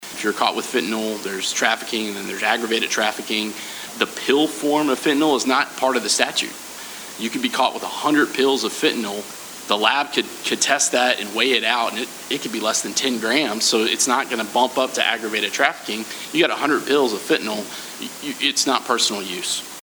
8th District State Representative Walker Thomas, 9th District State Representative Myron Dossett, and 3rd District State Senator Craig Richardson shared some of those Thursday night at a Town Hall.